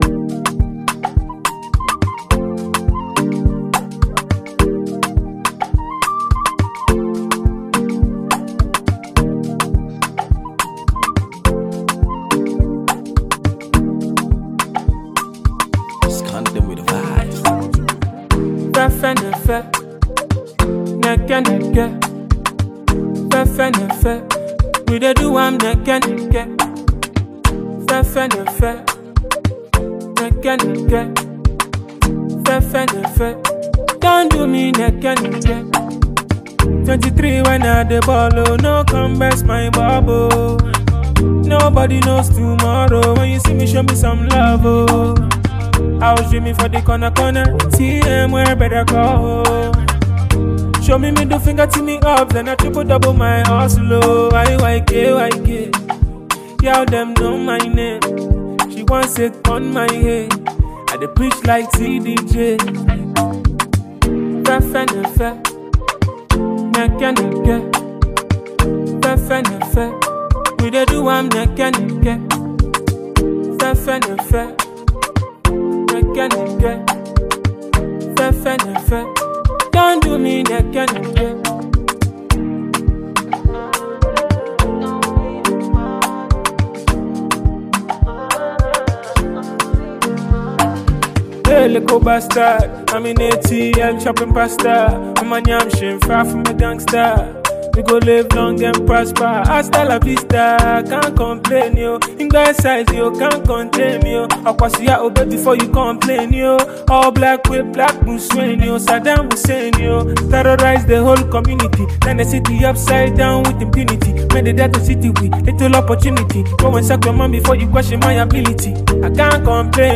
afropop
hit song with a unique sound